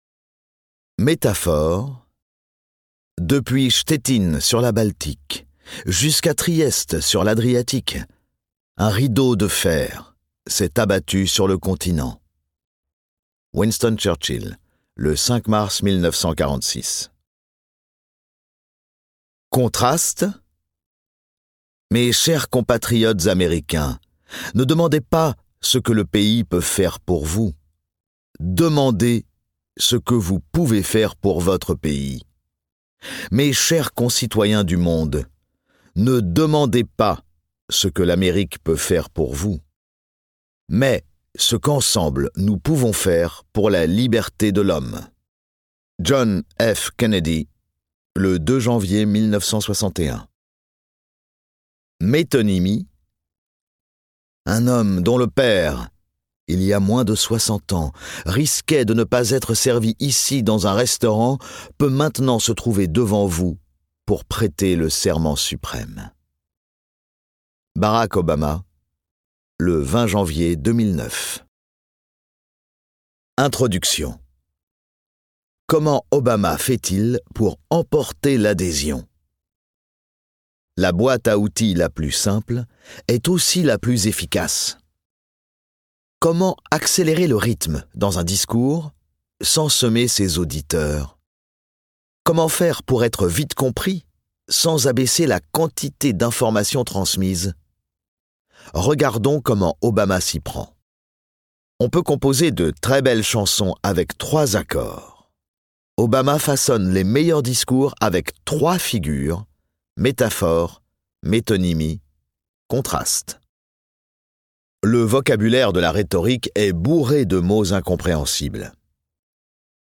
Dans ce livre audio court et efficace, l'auteur analyse les stratégies rhétoriques de l'ancien président des États-Unis et celles d'autres grands orateurs comme Robert Badinter et Simone Veil.